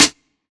Snare African Night.WAV